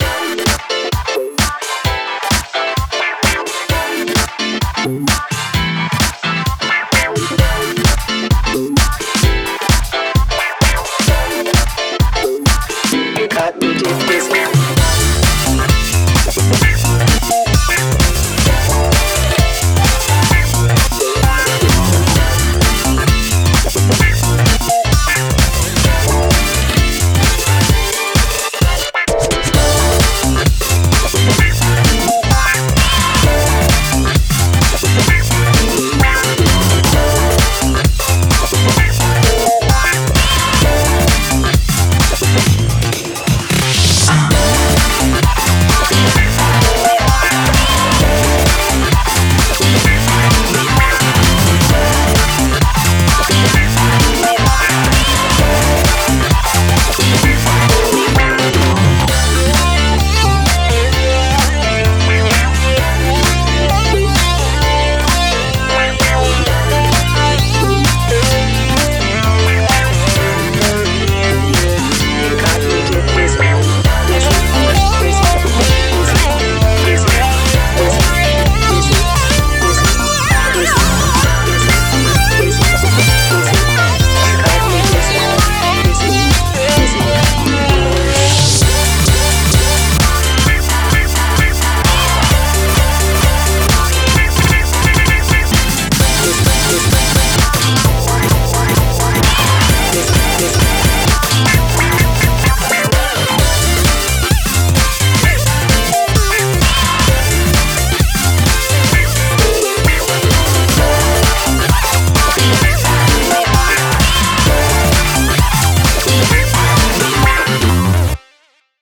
BPM130
Audio QualityPerfect (High Quality)
Is disco supposed to be this sparkly...?